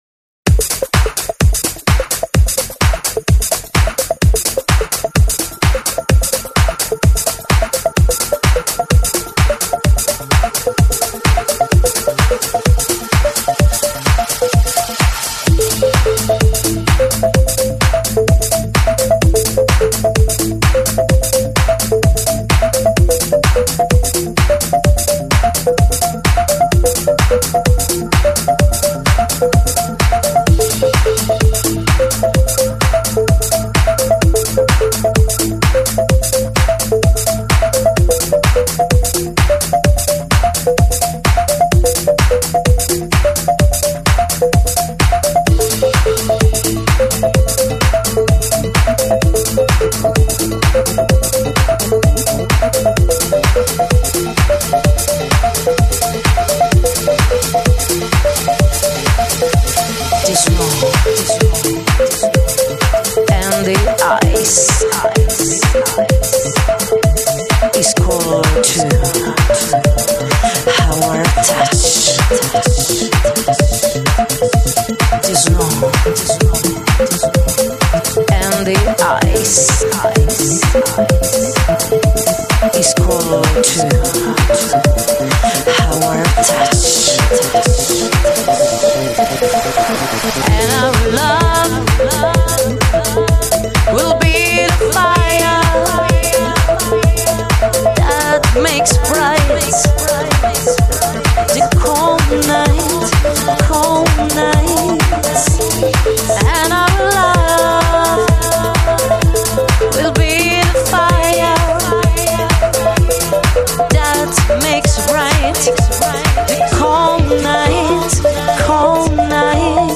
Bassline